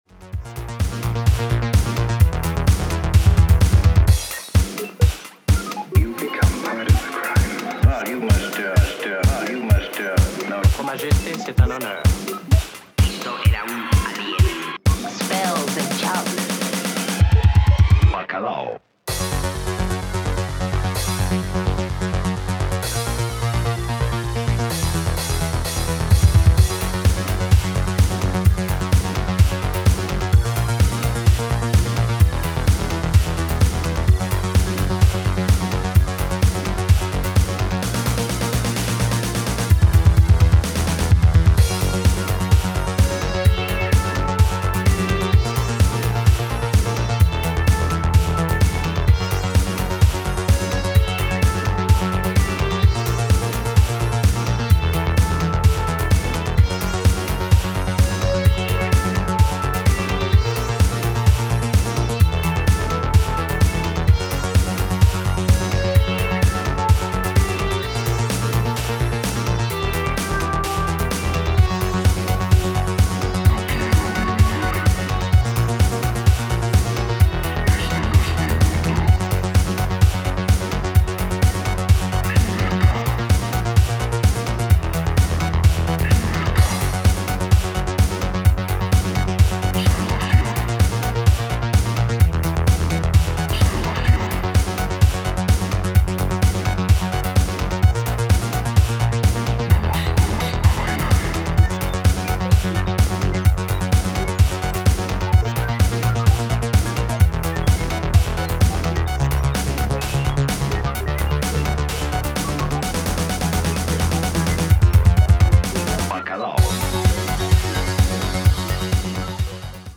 strange, otherworldly voice
cosmic electronics
Italo Disco